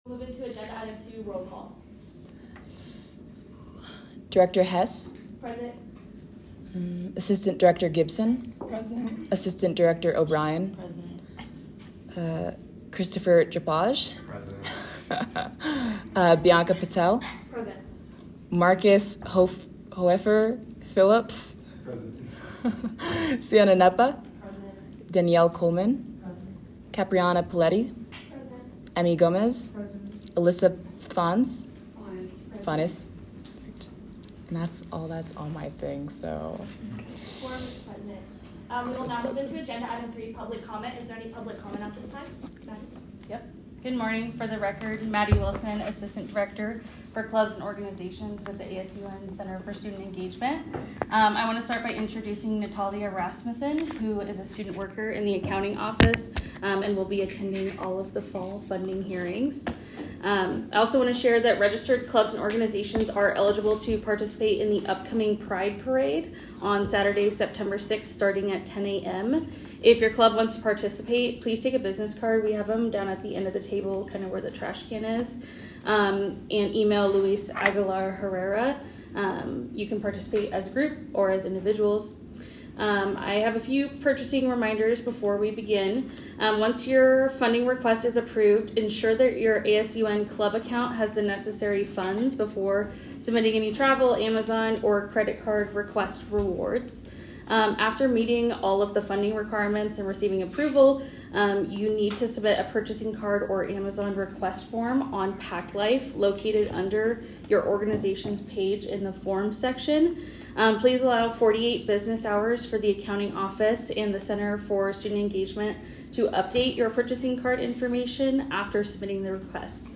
Location : Room 324 - located on the third floor of the JCSU